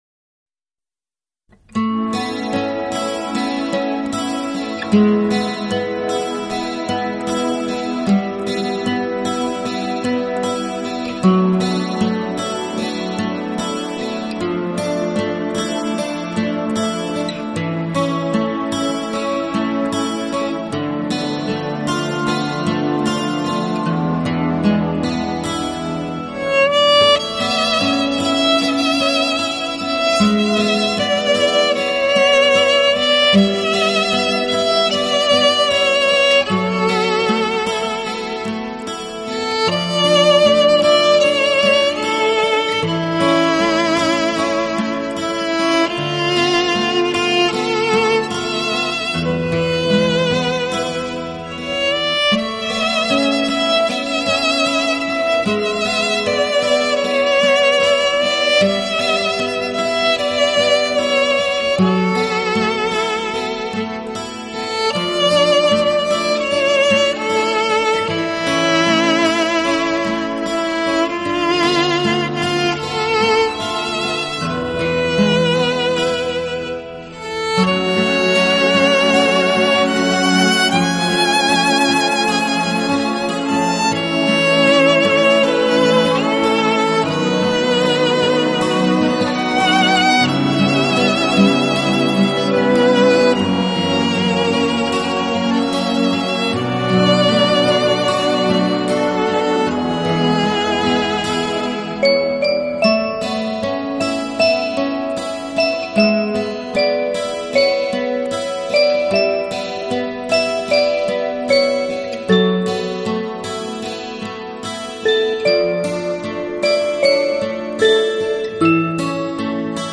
杂锦合辑
专辑语种：纯音乐
07年首张最好听的发烧天碟，乐器音色甜美，音场深阔，定位精确，其音质
之纯美，动态对比之鲜明和立体感之亮丽，简直就是近期发烧录音之冠。